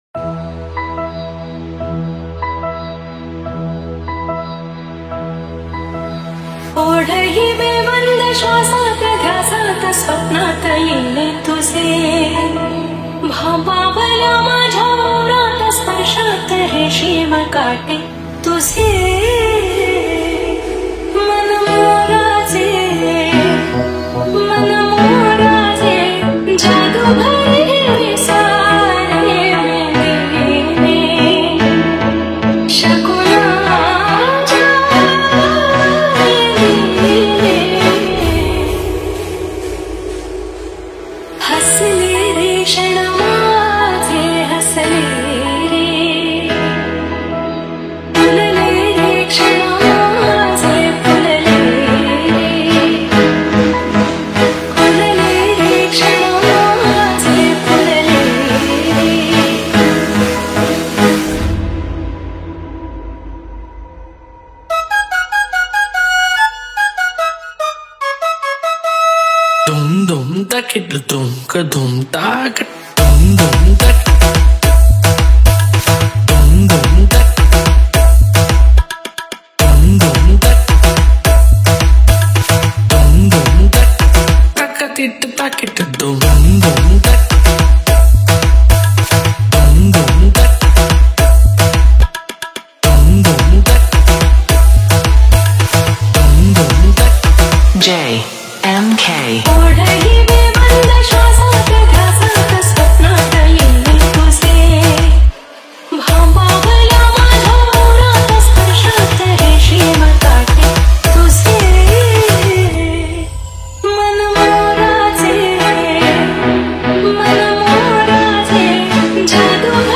• Category: MARATHI SOUND CHECK